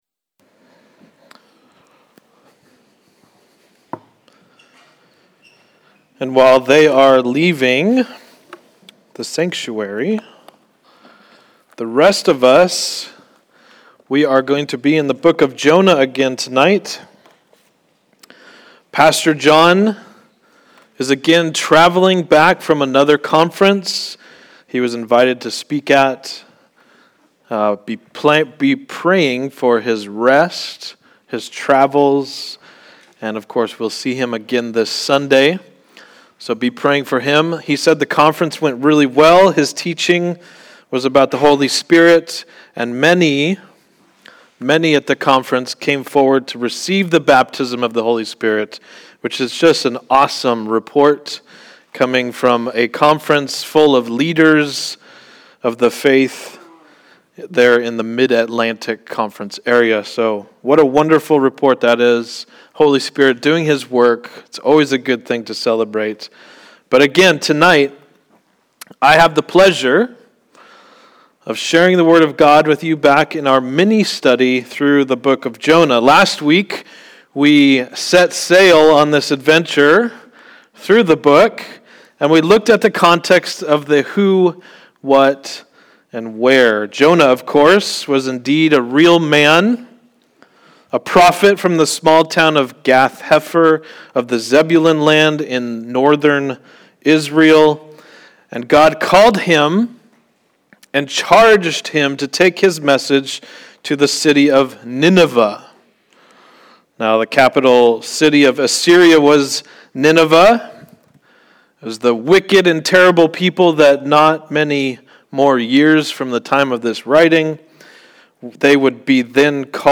Guest pastors sharing with us from the Word of God